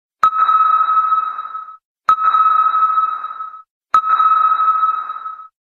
• Качество: 129, Stereo
короткие
Обычное СМС в котором 3 звука